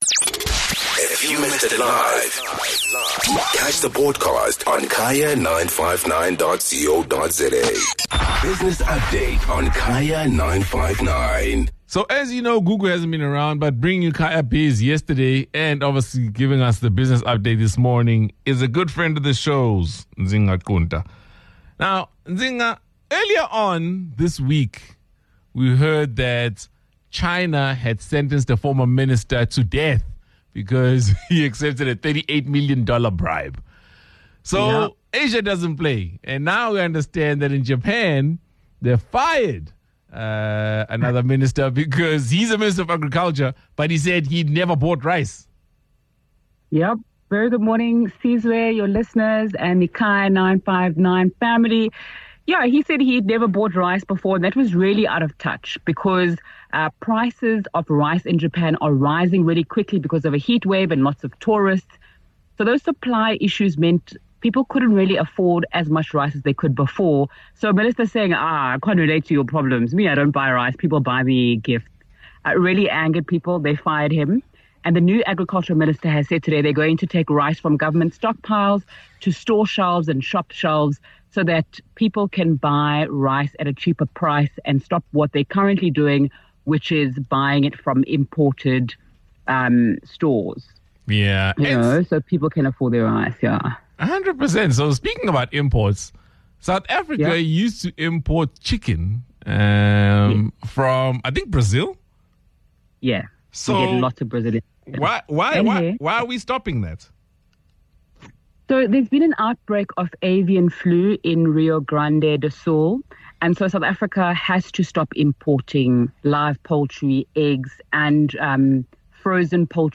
23 May Business Update - Japan firing its Agriculture Minister